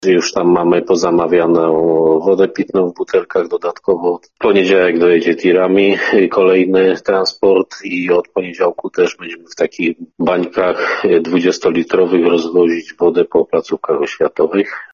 – Cały czas, niestety, bierzemy pod uwagę ten czarny scenariusz, bo tak trzeba działać – mówił nam Piotr Kucia, zastępca prezydenta Bielska-Białej.